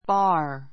bɑ́ː r バ ー